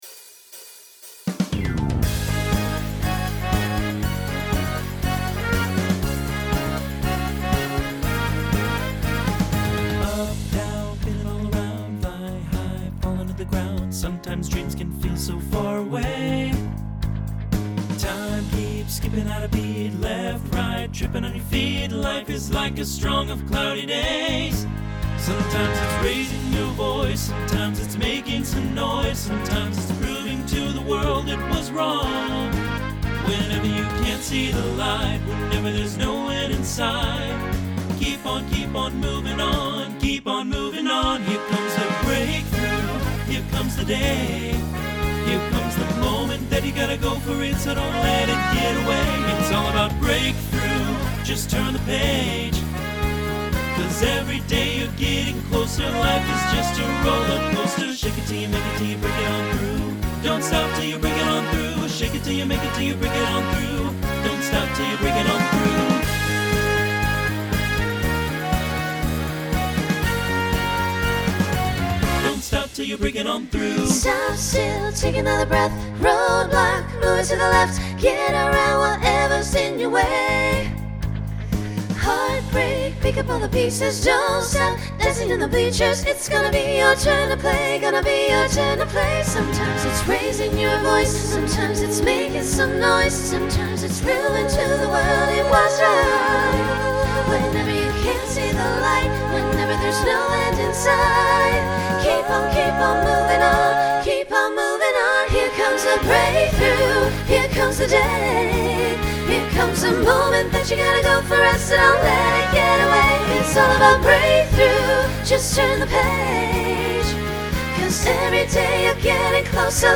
Pop/Dance
Transition Voicing Mixed